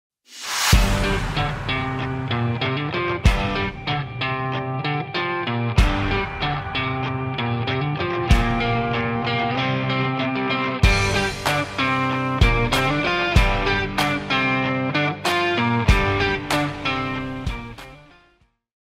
This small solid rocket motor sound effects free download